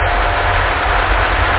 Ventilo.mp3